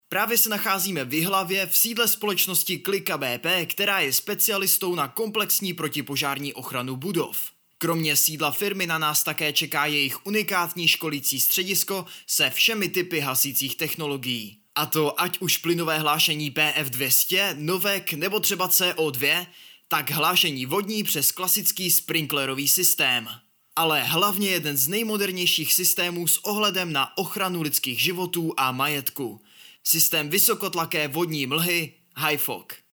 Hlas pro Vaše video! (voiceover)
K dispozici mám profesionální mikrofon a zvuk jsem schopen sám upravit a poté odeslat v nejvyšší možné kvalitě.
ukázka_reportáž.mp3